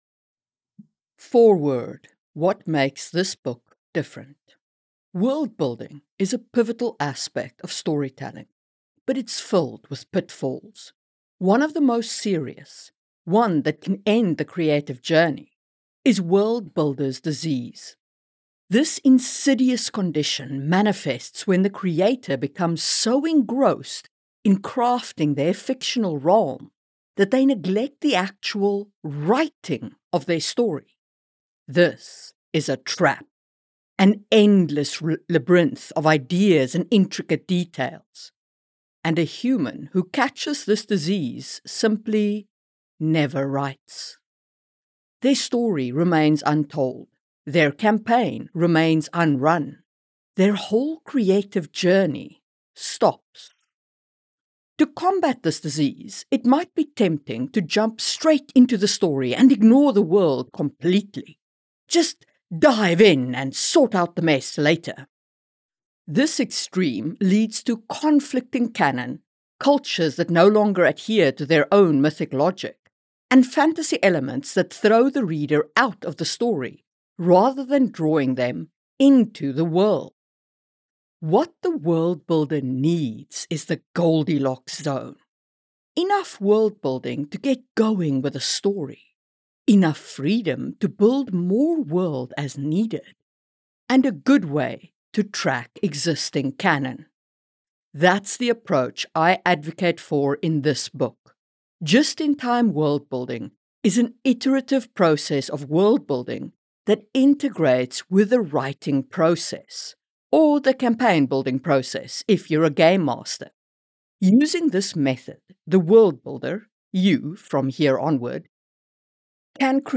Audiobook Foreword